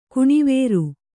♪ kuṇivēru